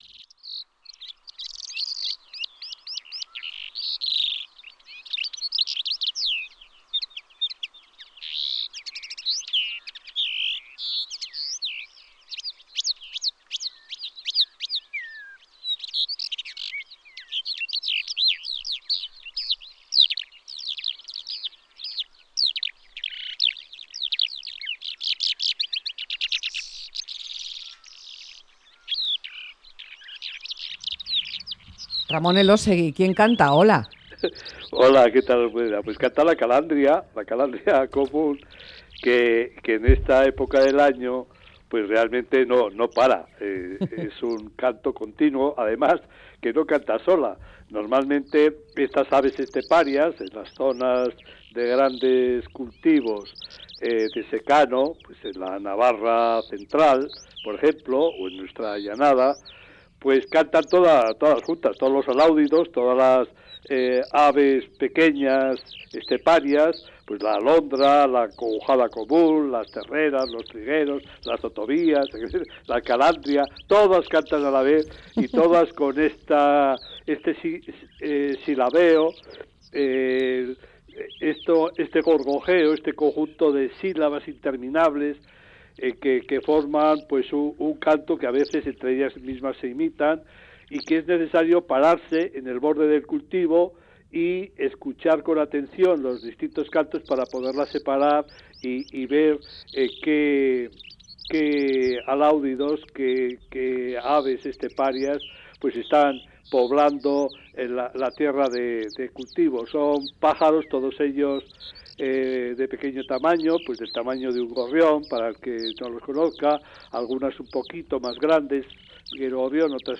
Calandria común